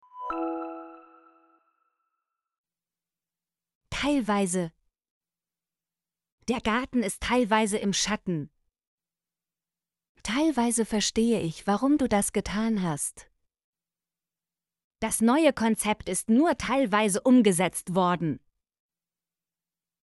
teilweise - Example Sentences & Pronunciation, German Frequency List